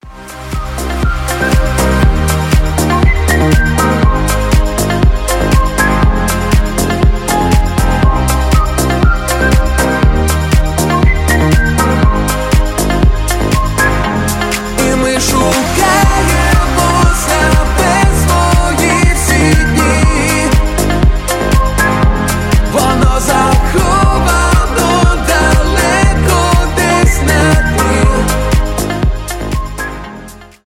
свист , поп , deep house , танцевальные